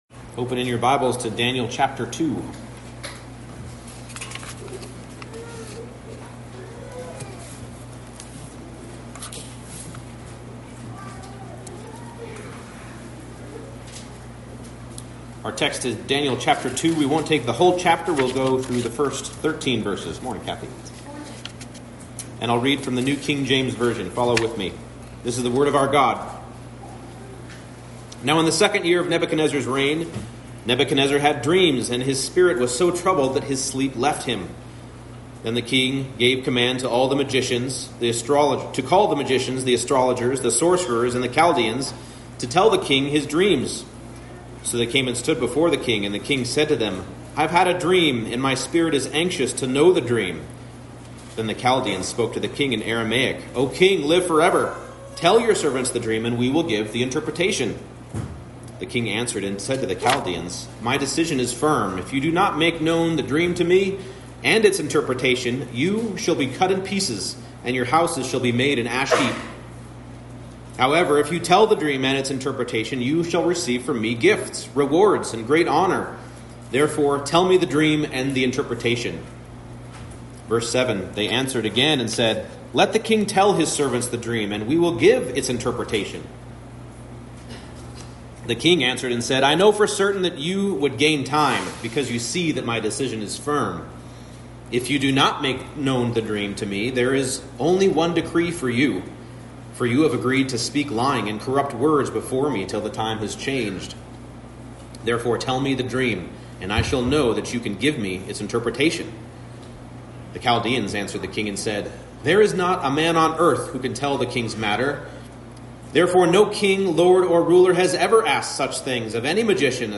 Daniel 2:1-13 Service Type: Morning Service God alone is the revealer of mysteries and the one who dwells with us